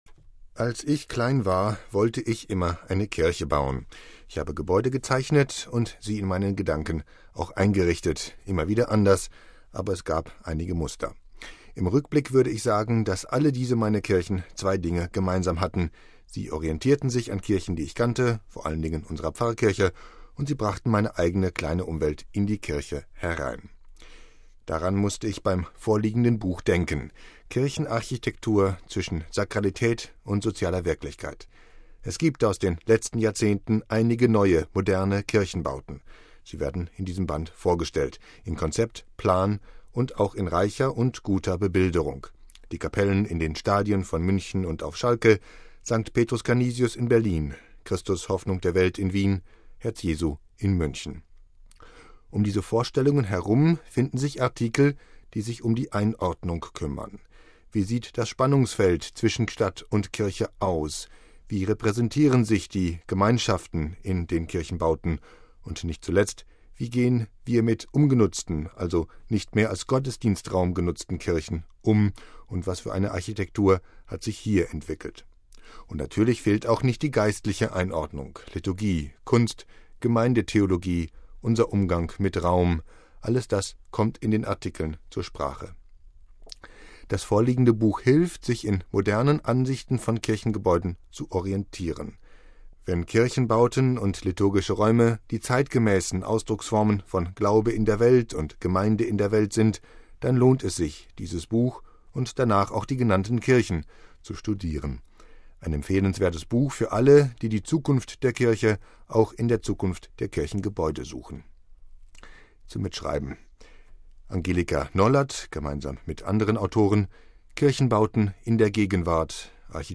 Unser Buchtipp